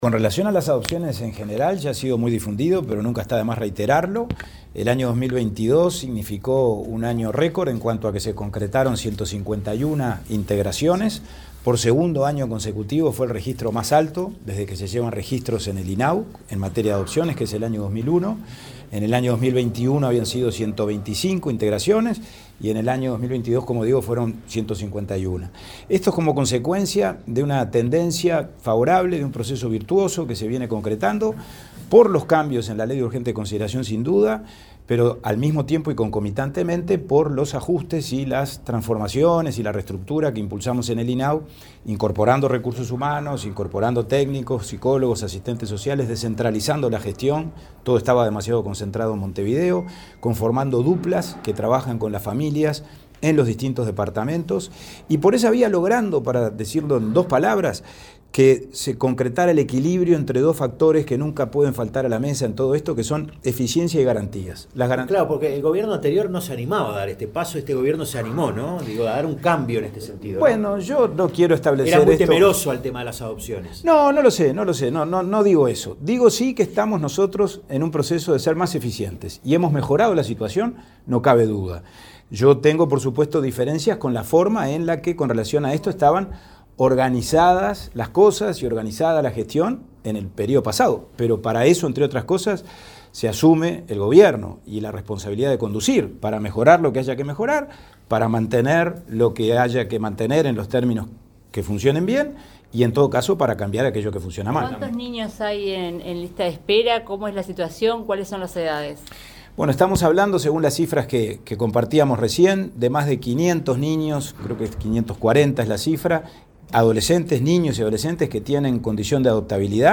Declaraciones del presidente del INAU, Pablo Abdala